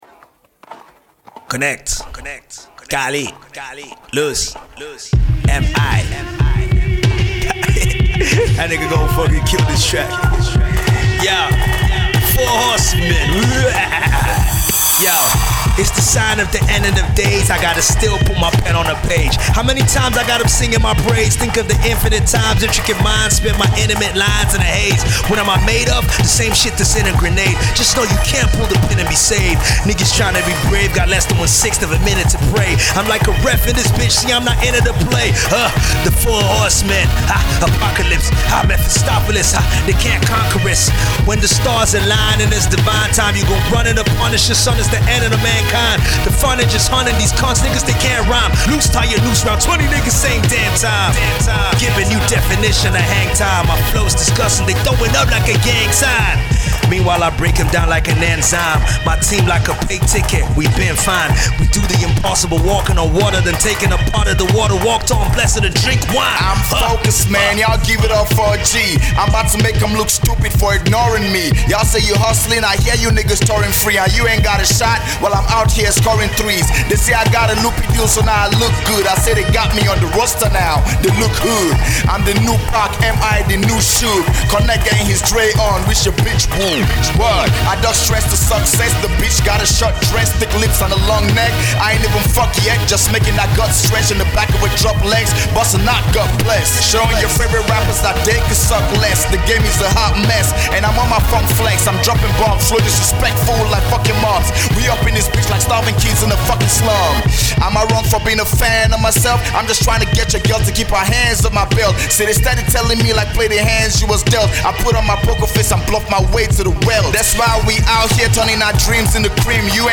is the result of an impromptu session
apocalypse-themed production